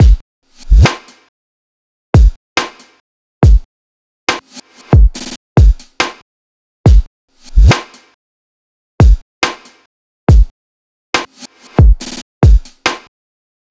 ドリル的なハットを取り入れつつも、今回はR&B的なリズムを意識しました。
そのままでもすごくノレる感じだったのですが、より打ち込み感を出したかったので、こういうアレンジをしています。
drum-1.wav